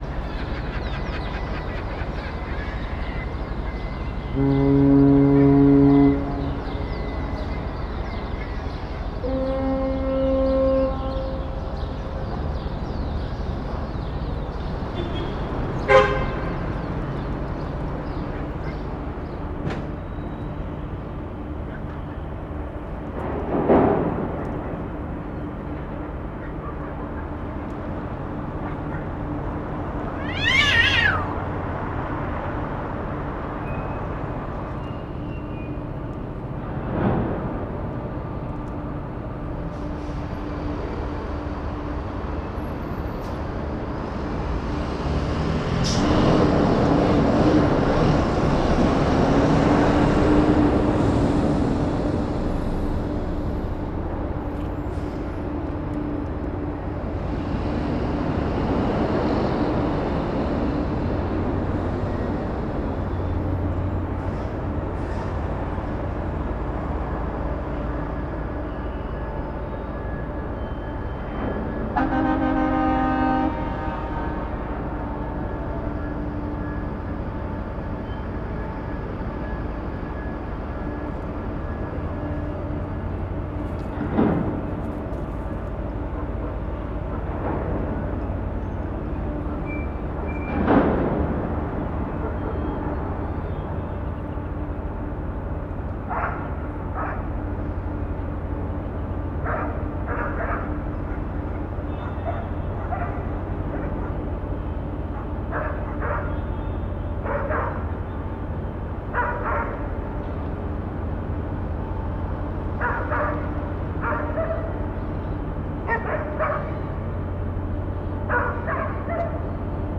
port-ambiance.mp3